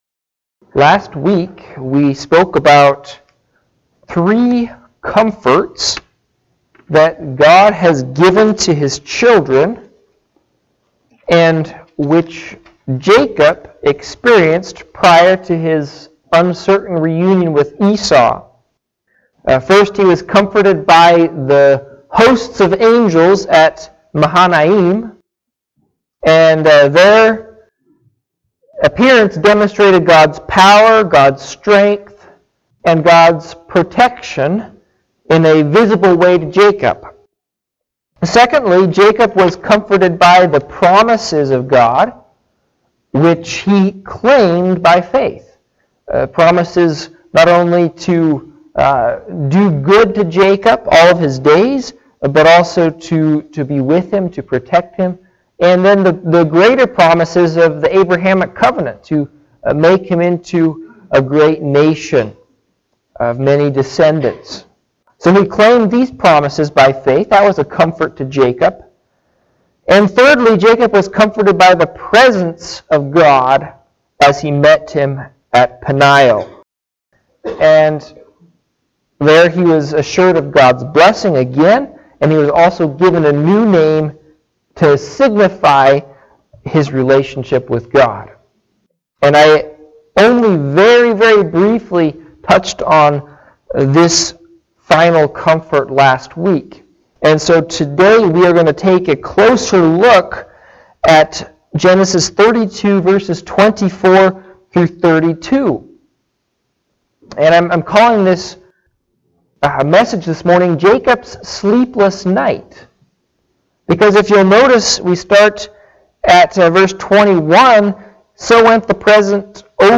Passage: Genesis 32:24-32 Service Type: Morning Sevice